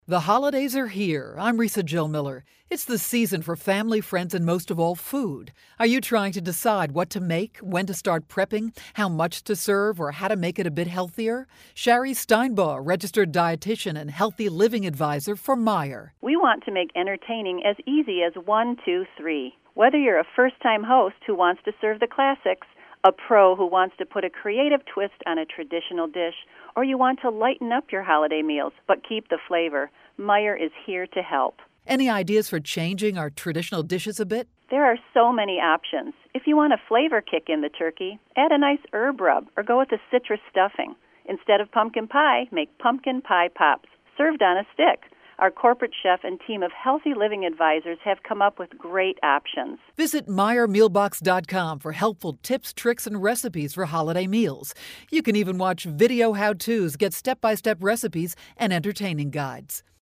November 27, 2012Posted in: Audio News Release